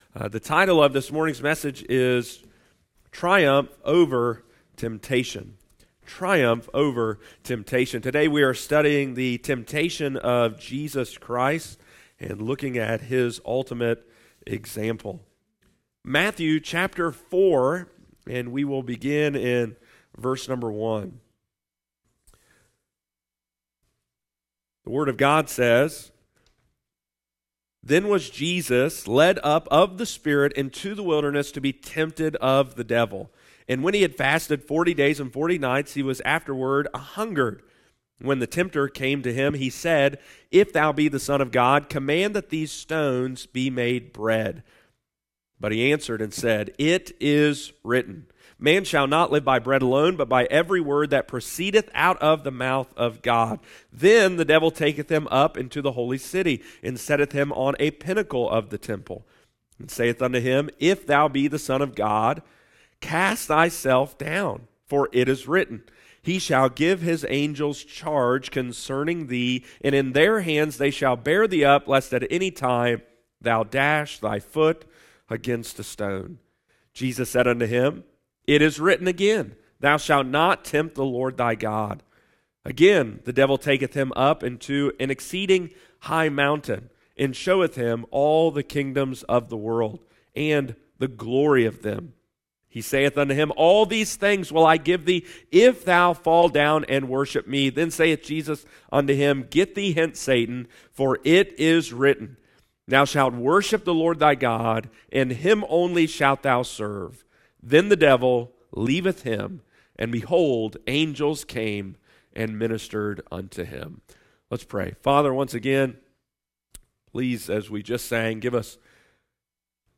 Triumph Over Temptation – Lighthouse Baptist Church, Circleville Ohio